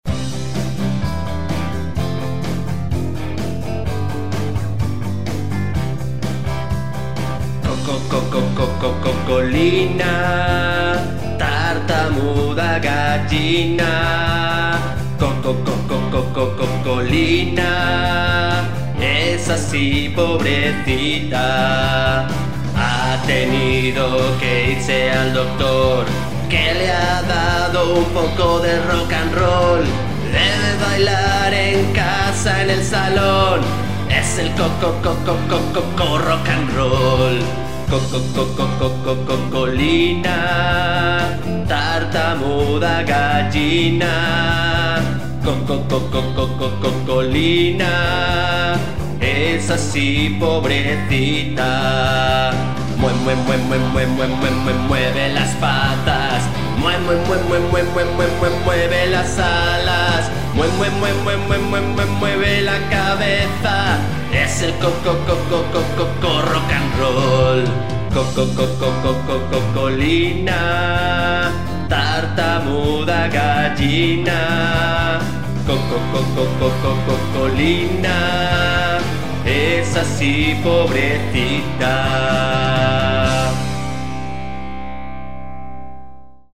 Canción para cantar y moverse.